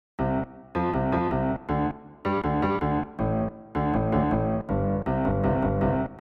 Tag: 120 bpm Chill Out Loops Percussion Loops 2.70 MB wav Key : Unknown